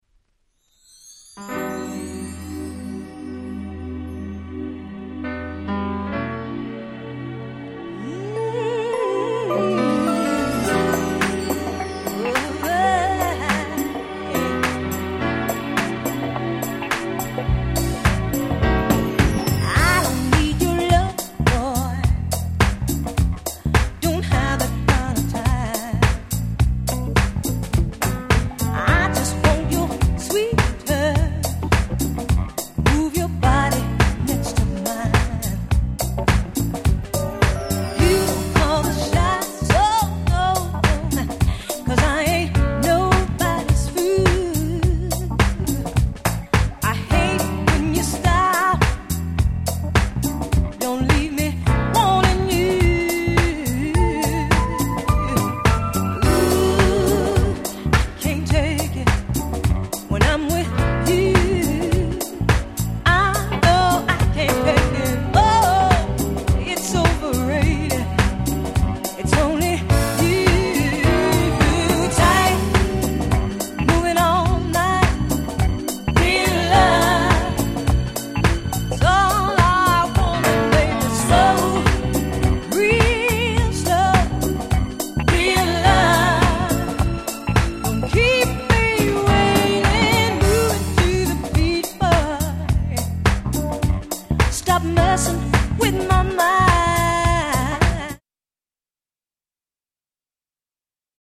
UK R&B Classics !!